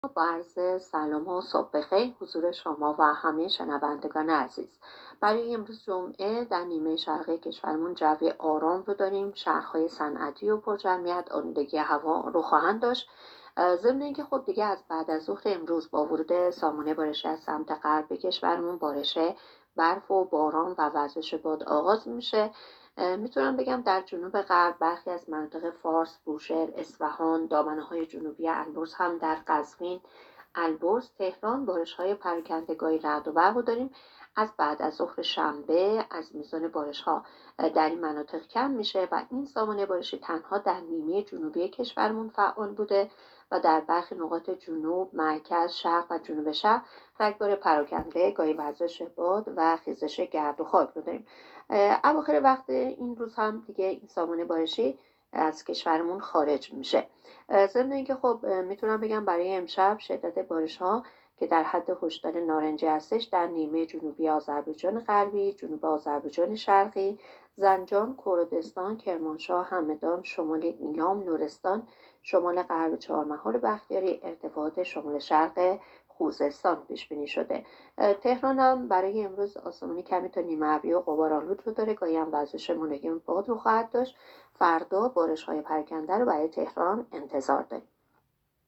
گزارش رادیو اینترنتی پایگاه‌ خبری از آخرین وضعیت آب‌وهوای ۳۰ آذر؛